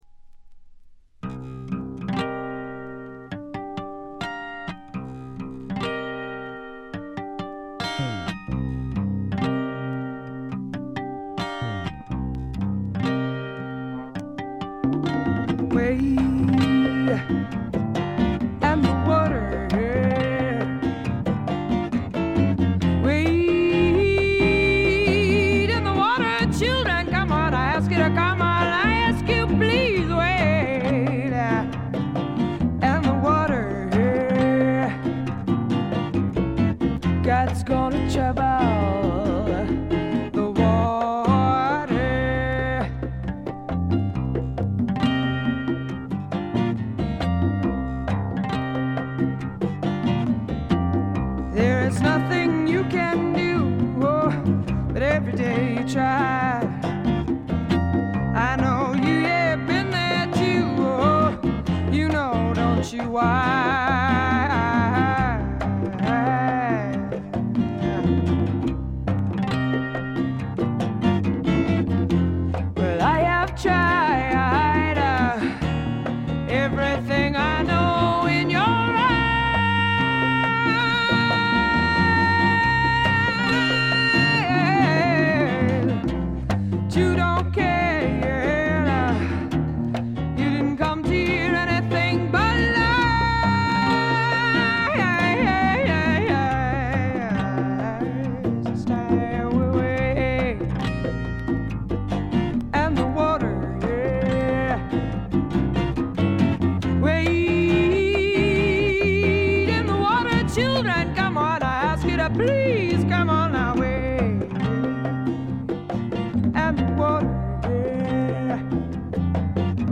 部分試聴ですがほとんどノイズ感無し。
試聴曲は現品からの取り込み音源です。
Side B Recorded at The Record Plant, N.Y.C.